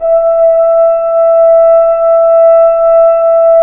Index of /90_sSampleCDs/Roland L-CDX-03 Disk 2/BRS_French Horn/BRS_F.Horn 3 pp